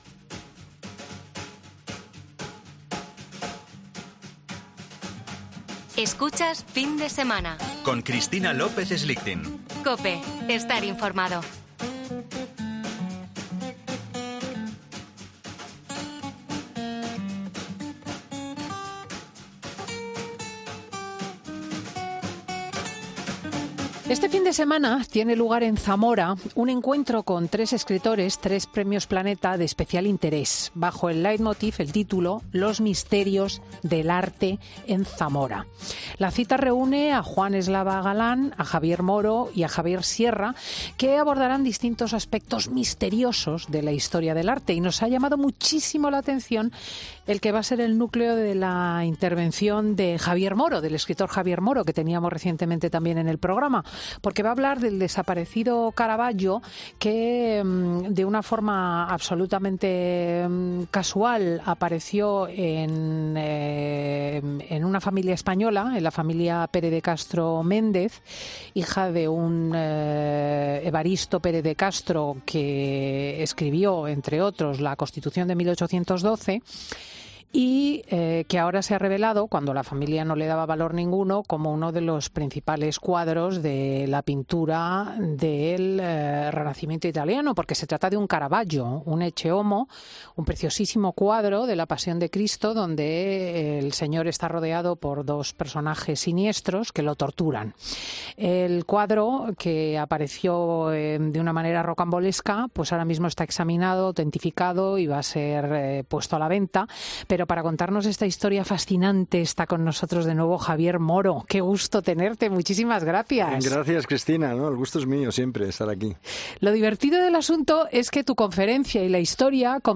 Javier Moro narra la aventura del descubrimiento de un cuadro único del genio renacentista italiano en una casa española